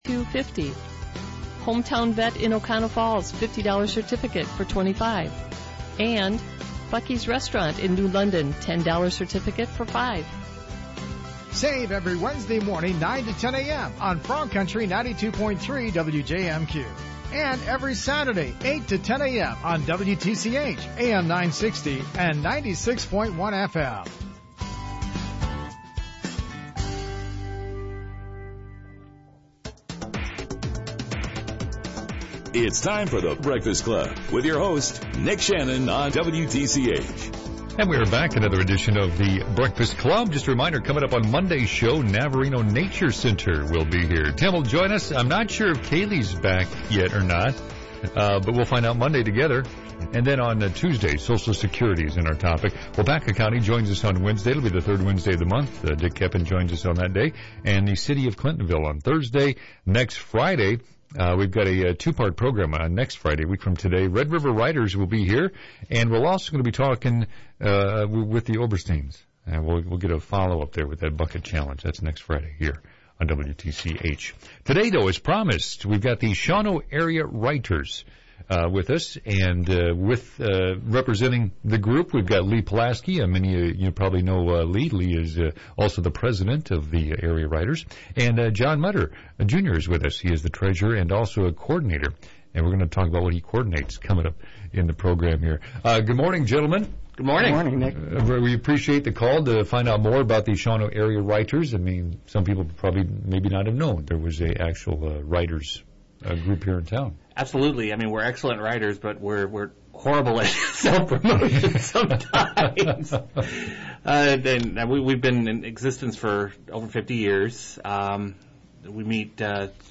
I wasn't talking specifically about my books in this interview for The Breakfast Club, a morning show broadcast on WTCH in Shawano, Wisconsin. I was promoting the Shawano Area Writers and the programs the group does.